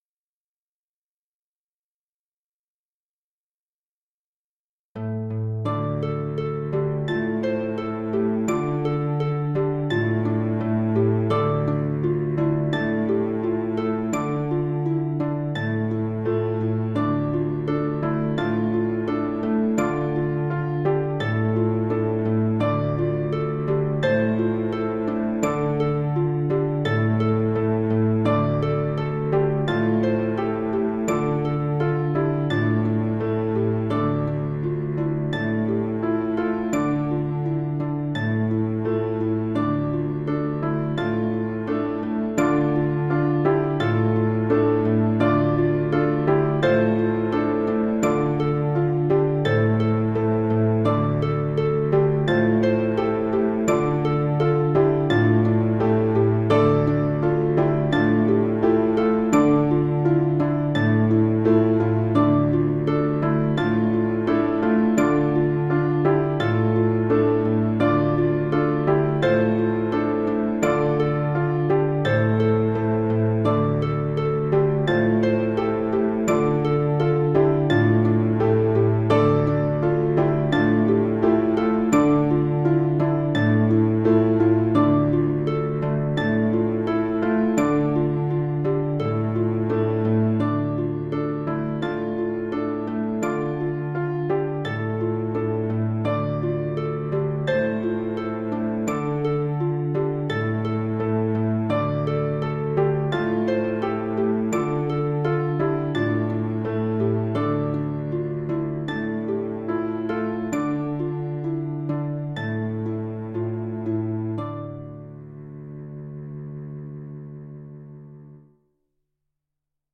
rehearsal recording